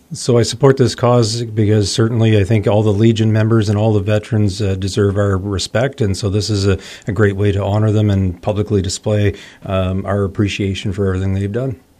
Pembroke Mayor Ron Gervais stopped by the myFM studios on Tuesday to personally donate to the cause and to offer his endorsement of the program:
ron-gervais-on-104-hearts.mp3